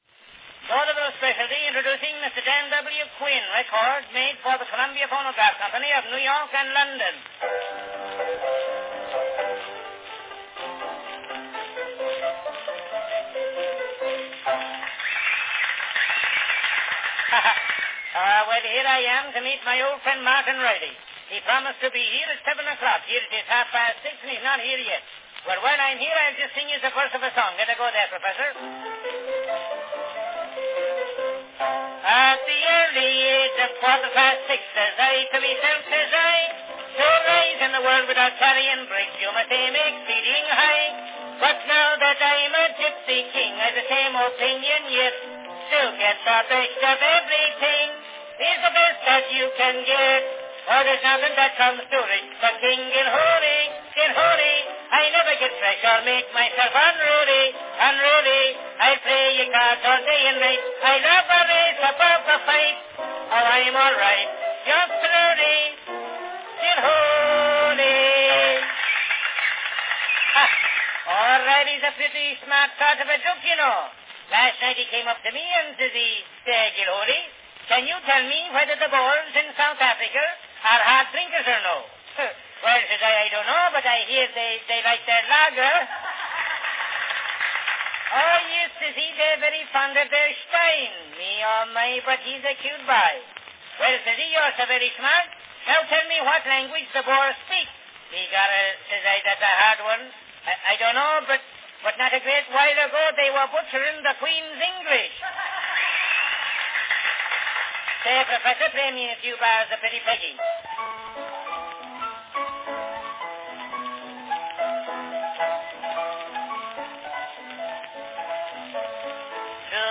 From 1901, an early vaudeville-style recording providing brief topical turn-of-the-previous-century glimpses into the Boer War and of Irish integration into America.
Category Vaudeville series - Irish comedy talk
Performed by Dan W. Quinn
The versatile tenor & comic songster Dan Quinn announces and performs this 2-minute vaudeville-style routine of Irish ethnic topical jokes and songs.
This recording is on a large 5-inch diameter Columbia Phonograph Company "Grand" cylinder.